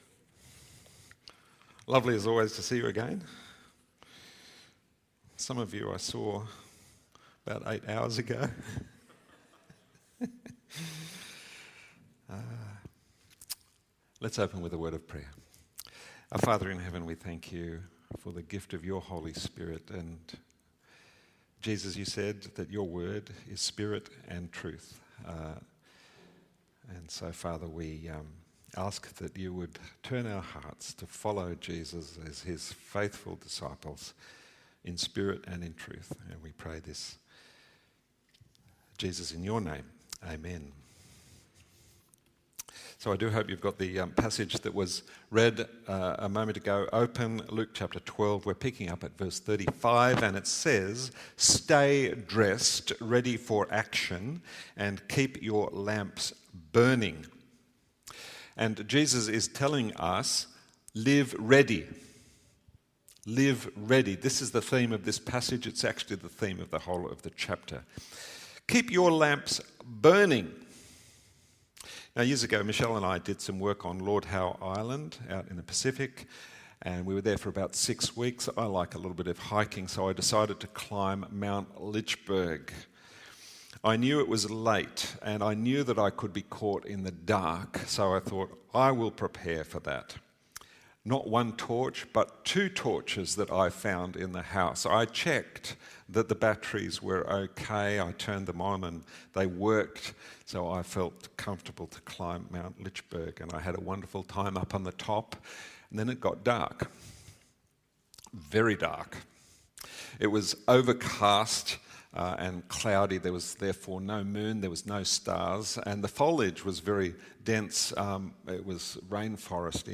Sermons | St Johns Anglican Cathedral Parramatta